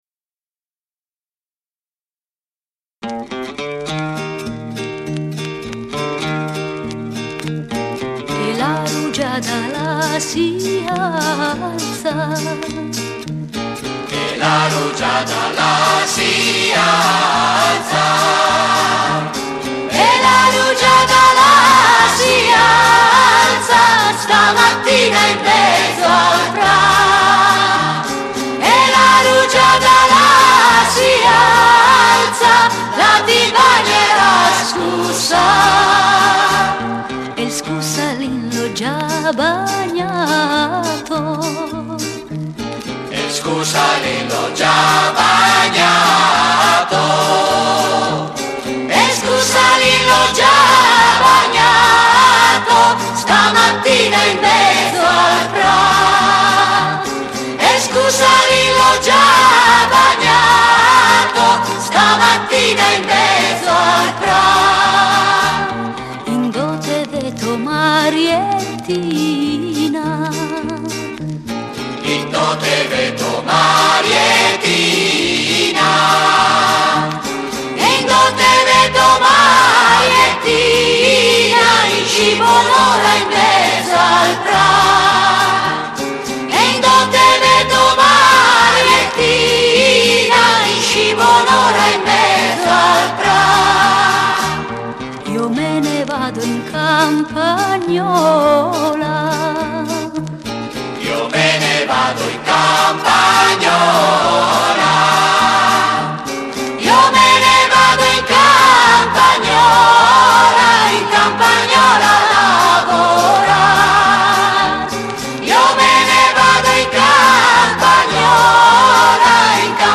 canto popolare lombardo